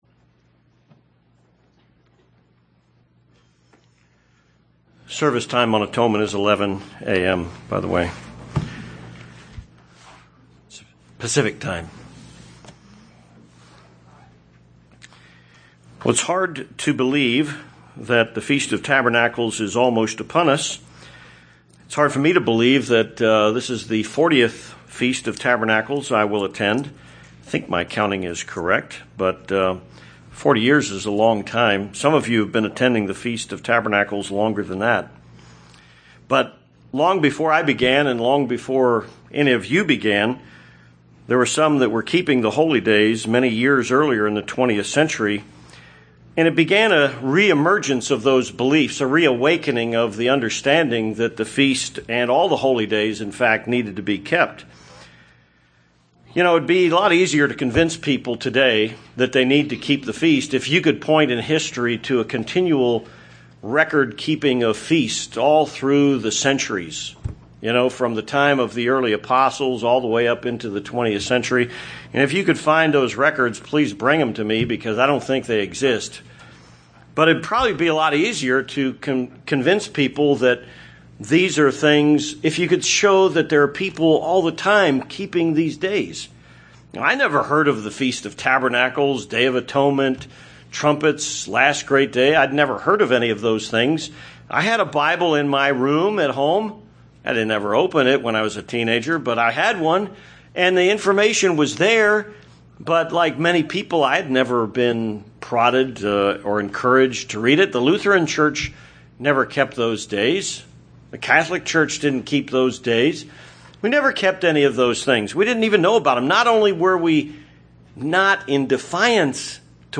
Given in Tucson, AZ
(Ephesians 2:19-20) UCG Sermon Studying the bible?